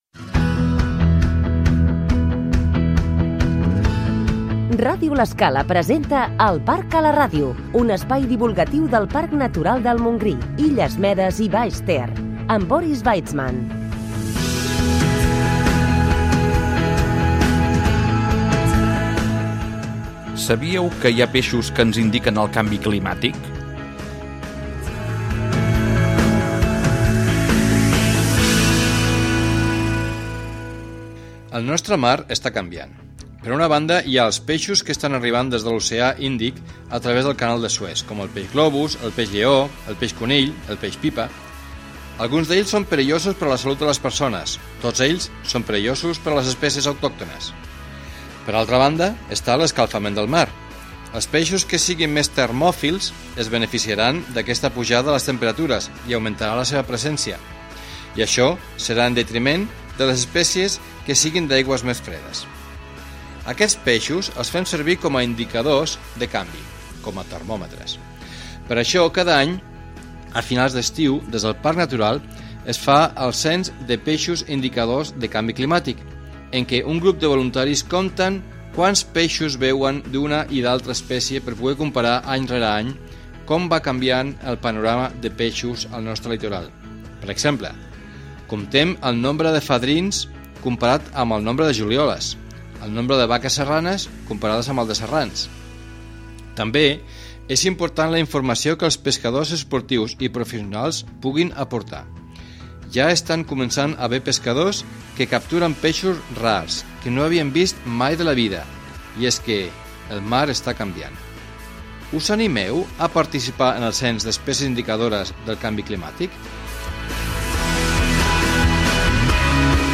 Un programa informatiu i divulgatiu de la riquesa i diversitat natural, de l'espai compres de parc natural Montgrí, Illes Medes i Baix Ter. Càpsules informatives de tres minuts